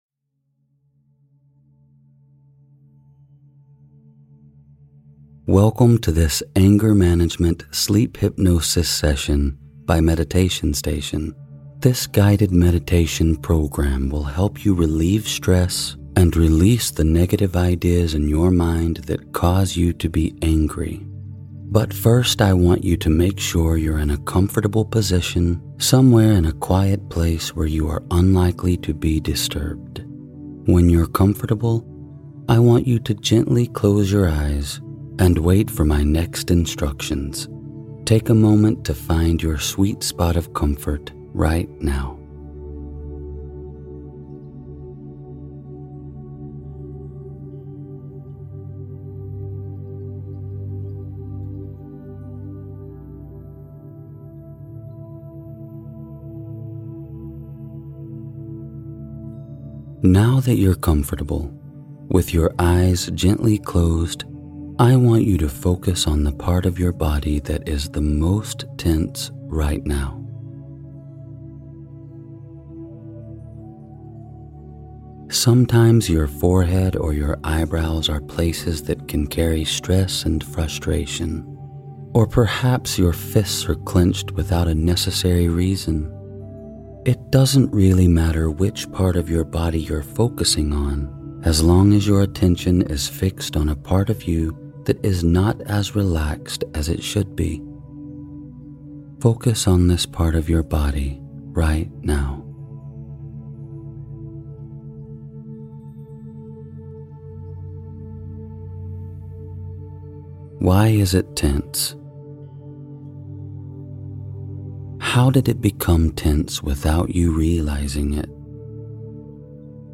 Anger Management Sleep Hypnosis Session